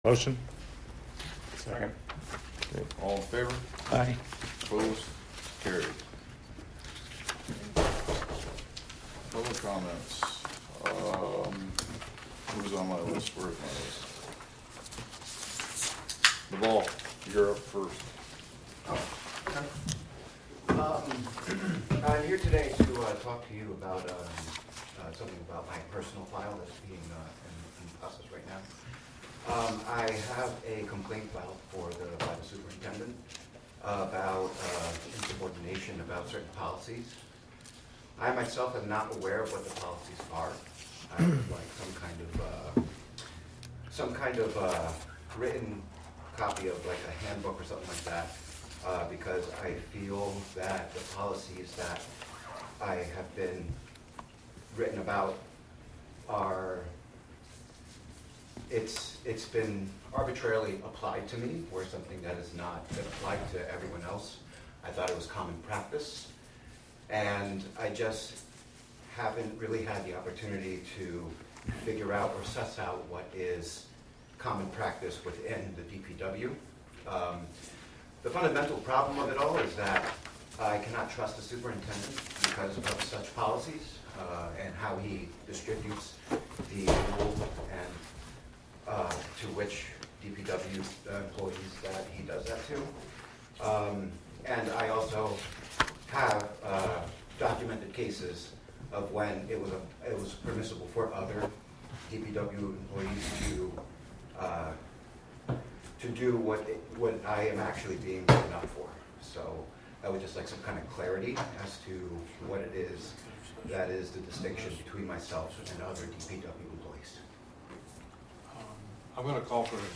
Live from the Village of Philmont